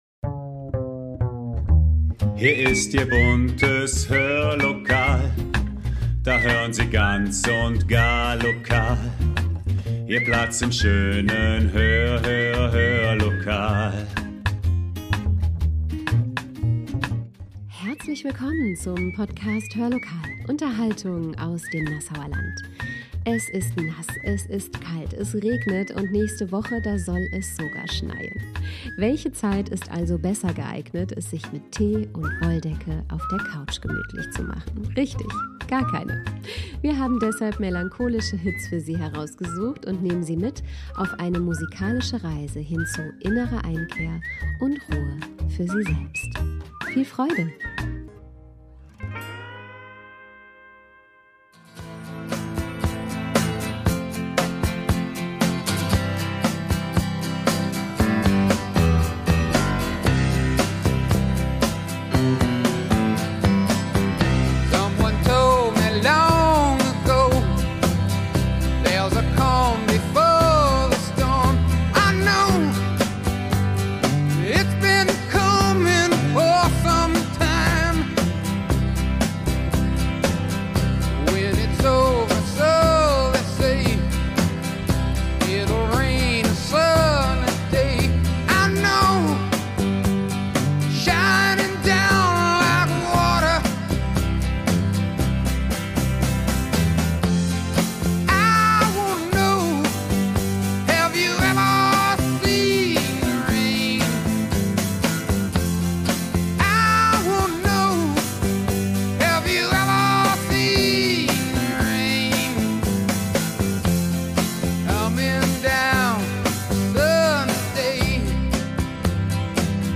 Wir haben deshalb melancholische Hits für Sie herausgesucht und nehmen Sie mit auf eine Reise hin zu innerer Einkehr und Ruhe für Sie selbst.